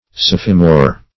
sophimore - definition of sophimore - synonyms, pronunciation, spelling from Free Dictionary
sophimore.mp3